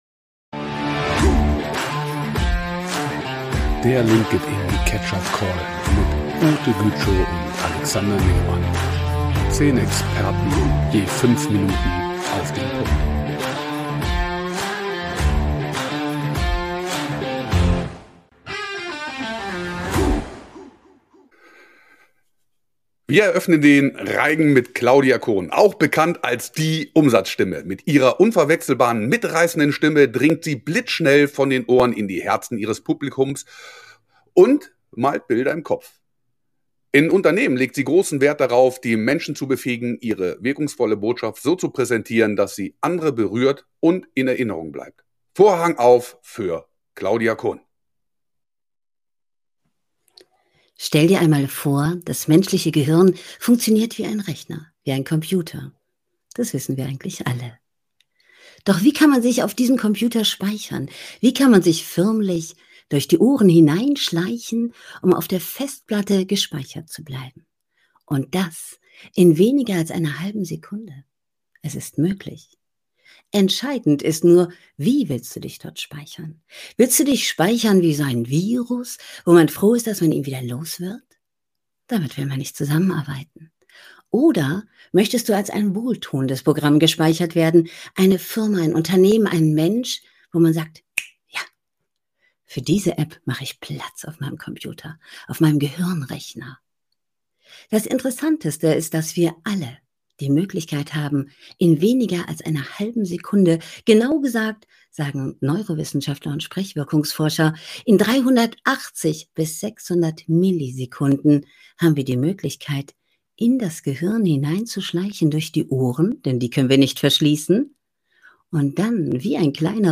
unverwechselbaren, mitreißenden Stimme dringt sie blitzschnell von